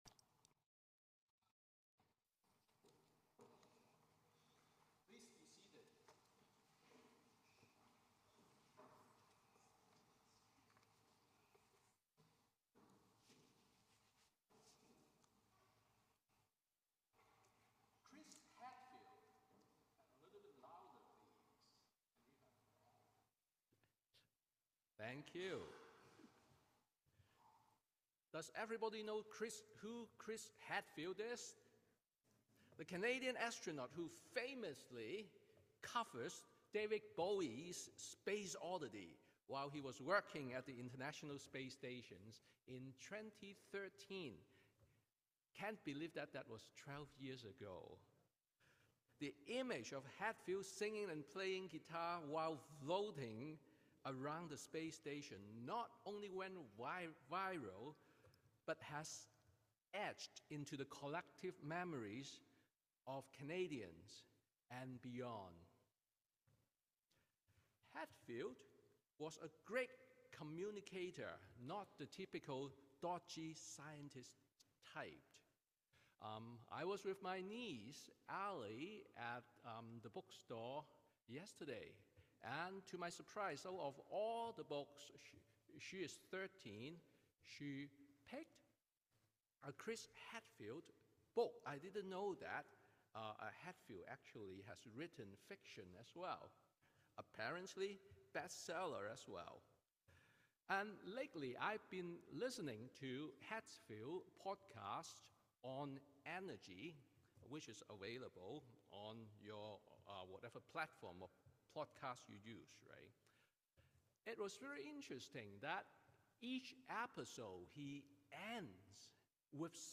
Sermon on the Eleventh Sunday after Pentecost